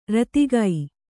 ♪ ratigai